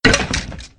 snd_skeletonbossarrow.ogg